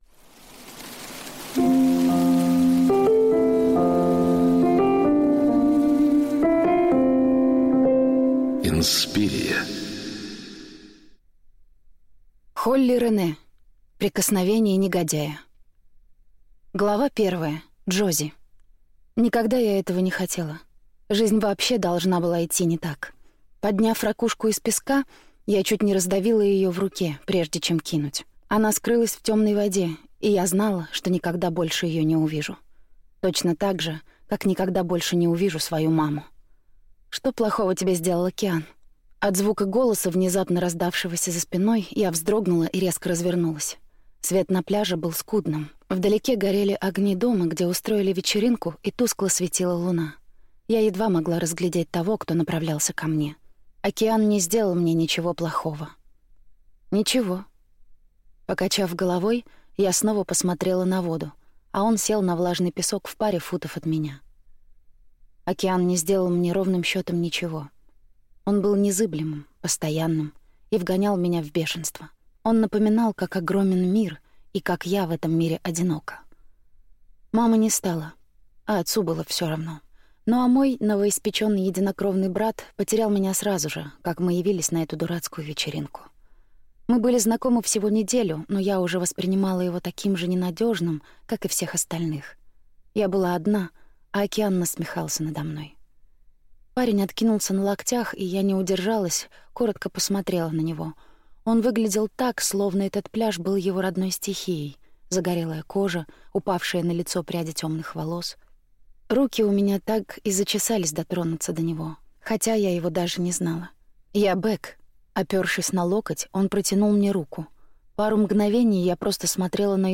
Аудиокнига Прикосновение негодяя | Библиотека аудиокниг